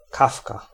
Ääntäminen
Synonyymit choucas des tours Ääntäminen France: IPA: [ʃu.ka] Haettu sana löytyi näillä lähdekielillä: ranska Käännös Konteksti Ääninäyte Substantiivit 1. kawka {f} 2. kawka zwyczajna lintu Suku: m .